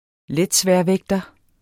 Udtale [ ˈlεdˌsvεɐ̯ˌvεgdʌ ]